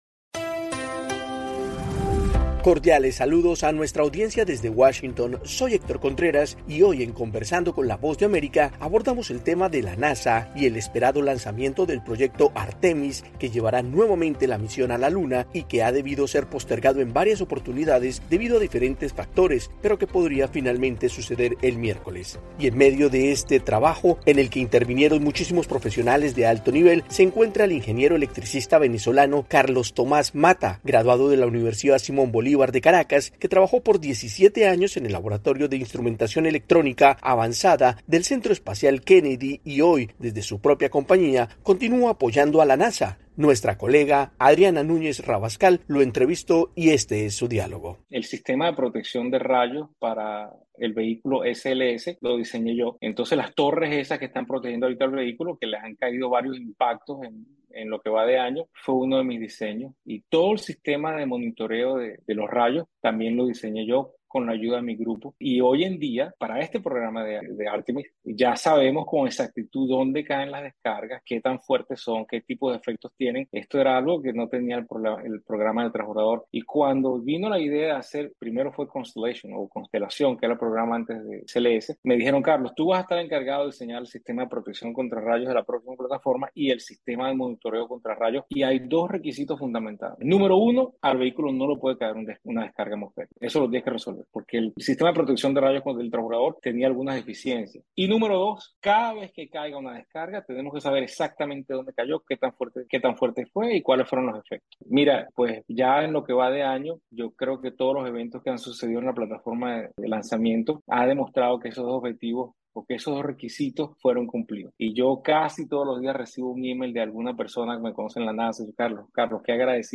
Conversamos con el ingeniero electricista venezolano